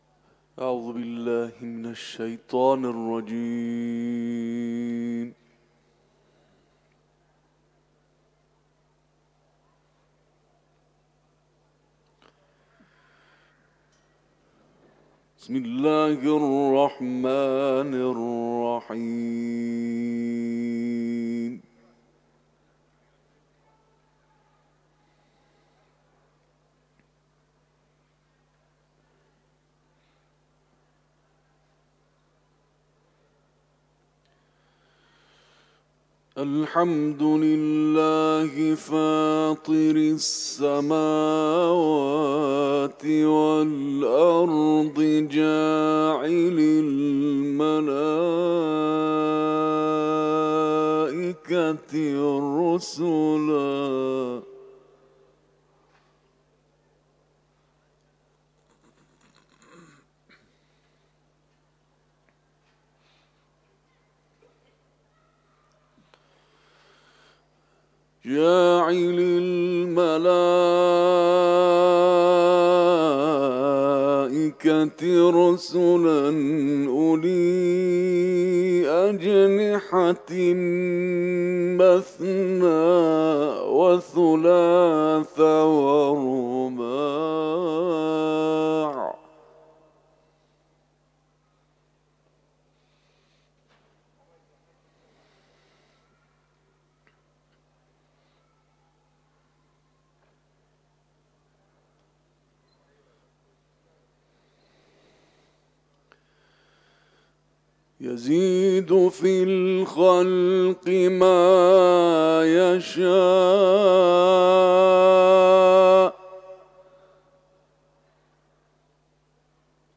صوت تلاوت آیاتی از سوره‌ «فاطر»